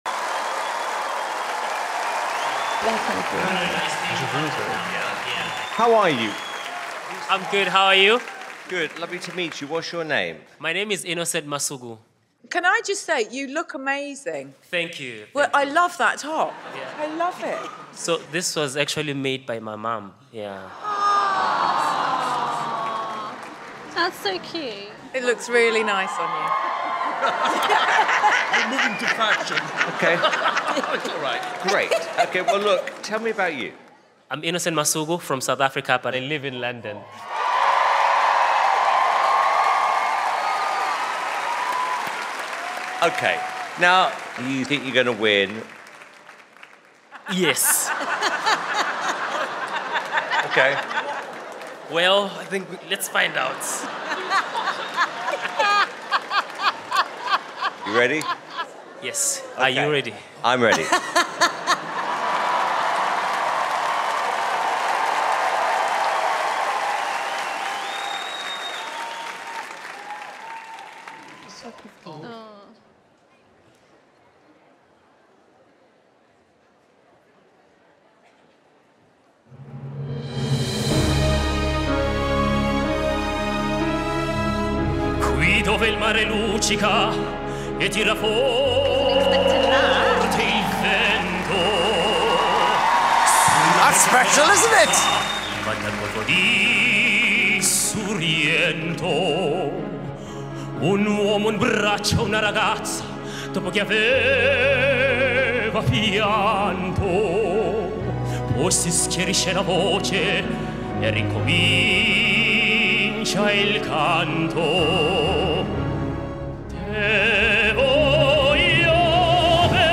Opera singer
Auditions BGT 202